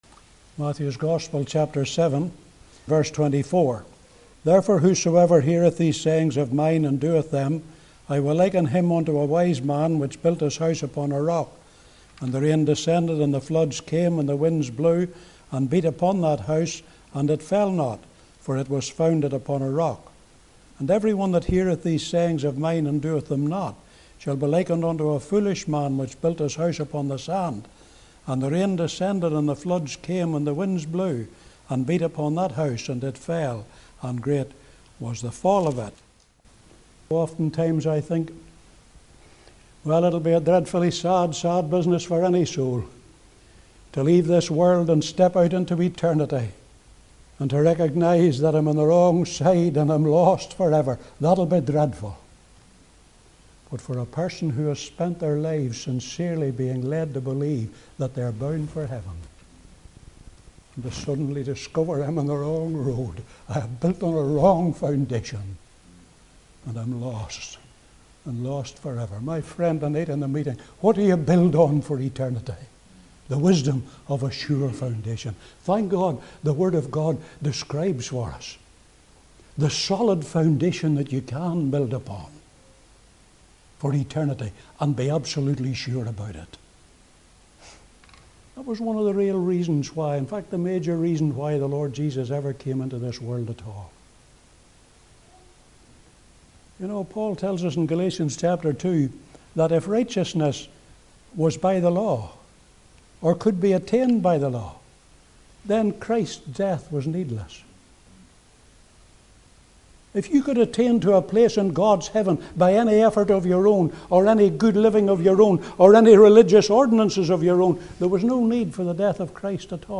This he found through the words of Isaiah 53:6 – “All we, like sheep have gone astray, we have turned – every one [of us] to his own way – and the LORD hath laid on Him [Jesus Christ] the iniquity of us all”  (Testimony given 7th June 2015)